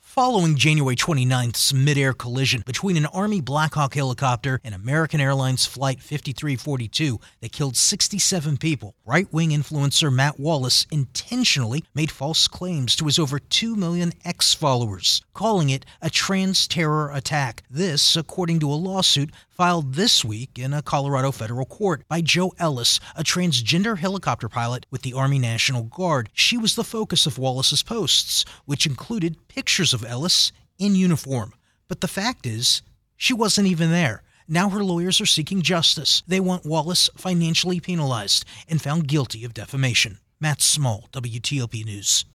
Live Radio
reports on a defamation lawsuit that has been filed against a right-wing influencer over false claims he made related to the deadly midair crash near Reagan National Airport earlier this year.